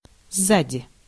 Ääntäminen
Ääntäminen Tuntematon aksentti: IPA: /ˈzːadʲɪ/ Haettu sana löytyi näillä lähdekielillä: venäjä Käännös Ääninäyte Adverbit 1. behind US UK Prepositiot 2. behind US UK 3. after US Translitterointi: szadi.